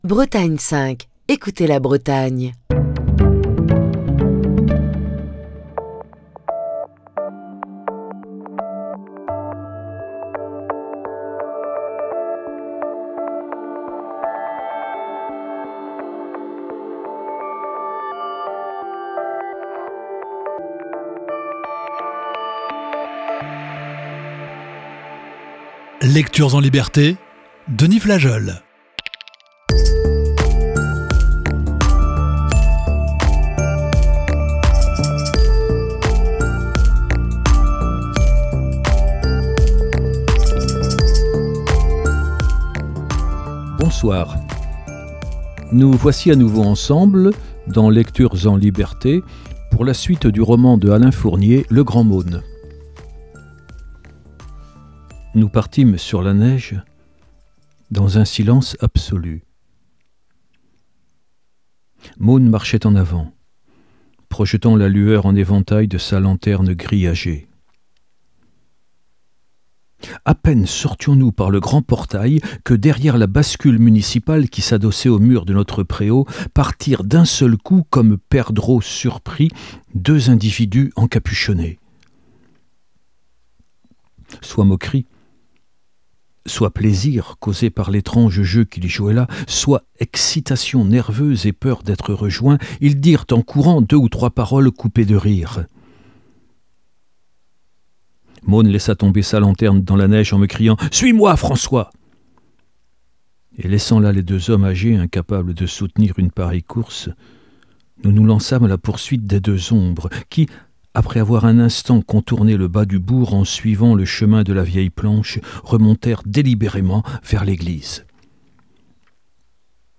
la lecture d'un classique de la littérature